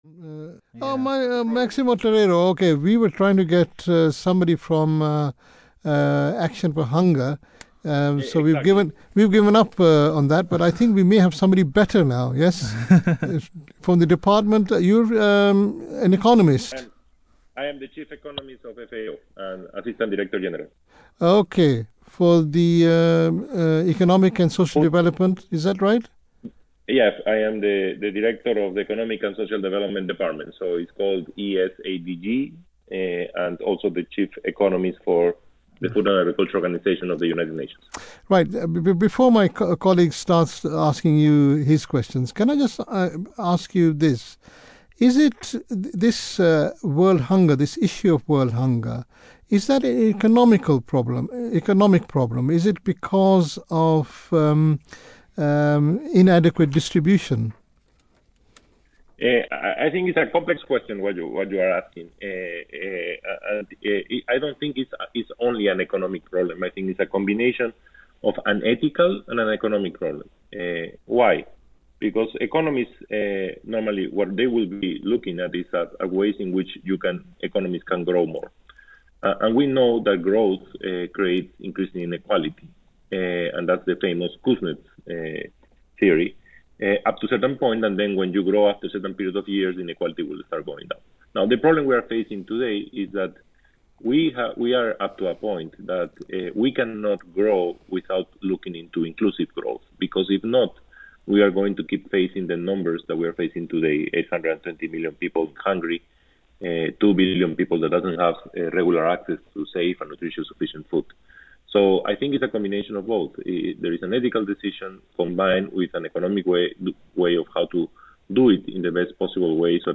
This podcast interview explains what the UN Food and Agriculture Organization is doing to tackle this complex challenge, while protecting the environment and empowering vulnerable people.
This podcast interview with the UK-based Voice of Islam highlights the UN Food and Agriculture Organization’s work — from bringing data to better understand the double burden of malnutrition to assisting countries overcome bottlenecks and achieve their potential — toward this great balancing act.